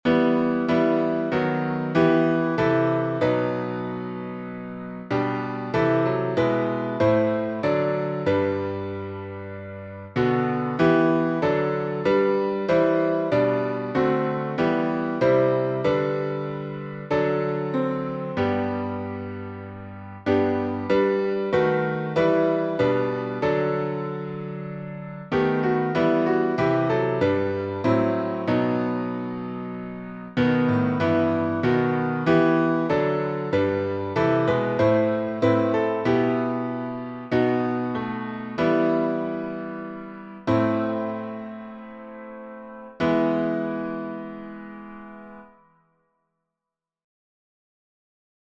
Words by Thomas Olivers, based on Jewish Doxology Tune: LEONI, Traditional Hebrew melody, adapted